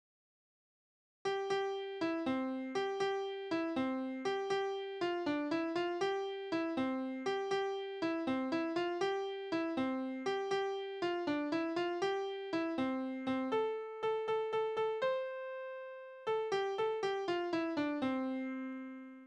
Kinderspiele: Tauben und Taubenhaus
Tonart: C-Dur
Taktart: 6/8
Tonumfang: Oktave
Besetzung: vokal